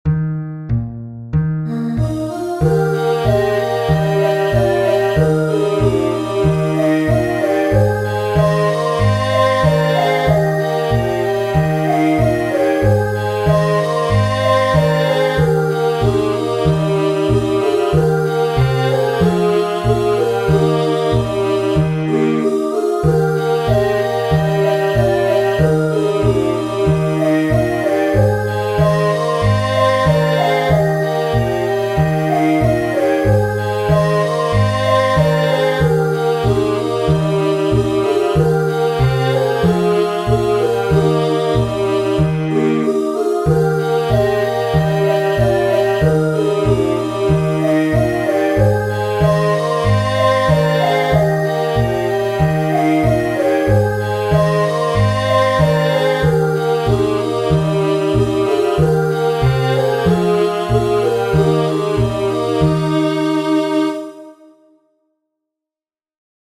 4-stimmiger Satz (SATB) mit Melodie im Alt
S A T B